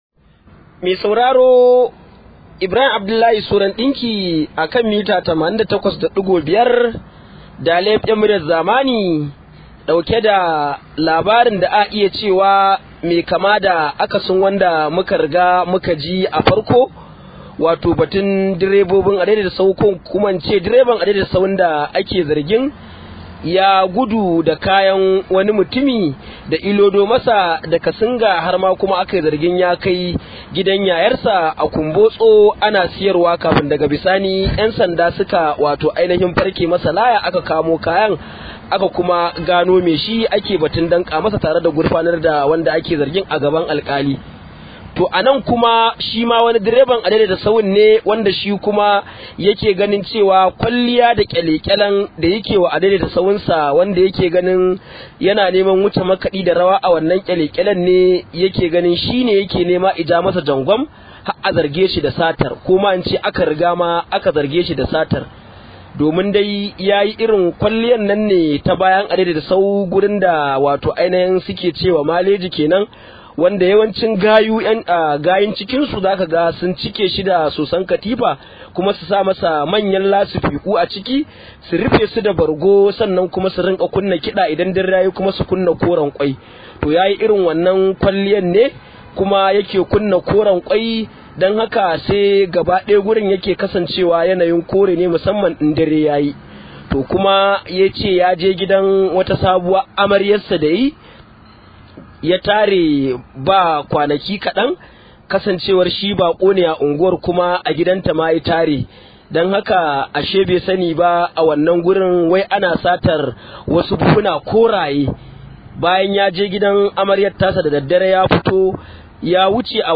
Rahoto: Direban adaidaita sahu ya gurfana a kotu kan zargin sata